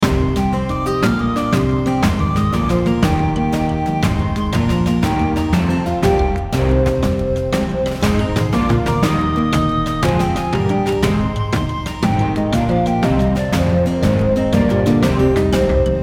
Download Video Game sound effect for free.